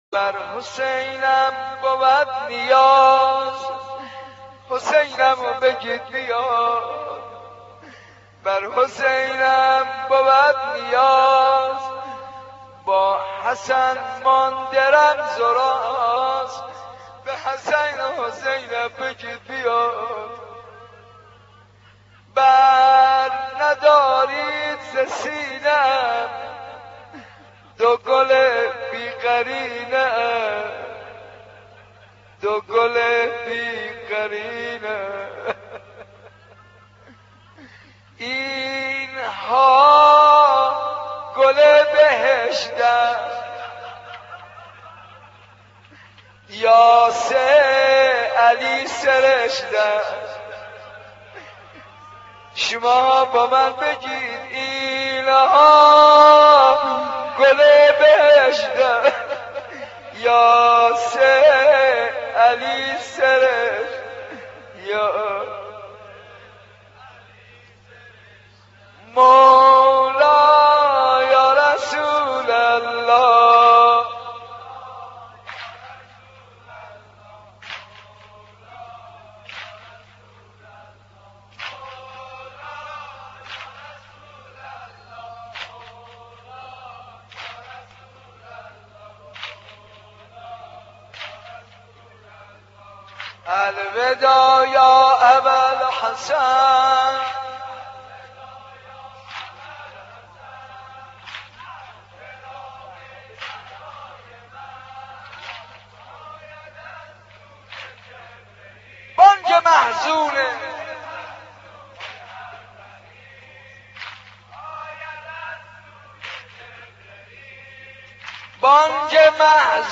دانلود مداحی بر حسینم بود نیاز با حسن مانده رمز و راز - دانلود ریمیکس و آهنگ جدید
روضه خوانی به مناسبت شهادت حضرت فاطمه(س) توسط حاج منصور ارضی (4:27)